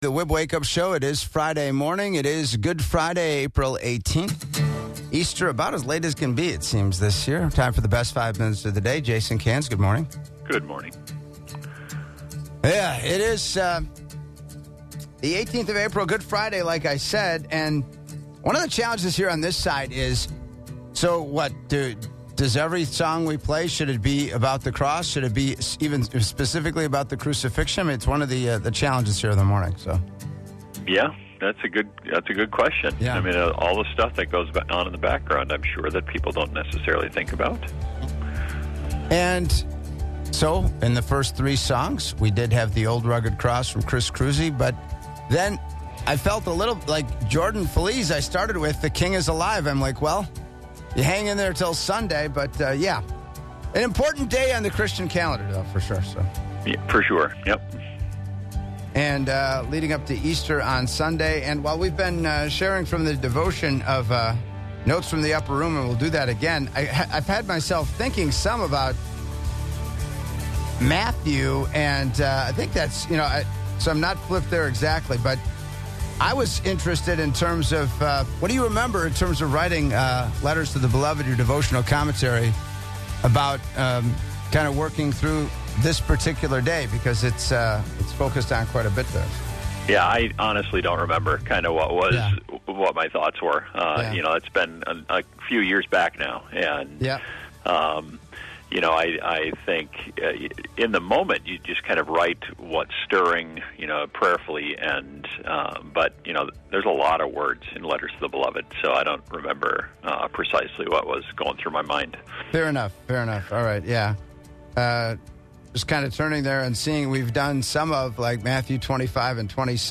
Appreciation Call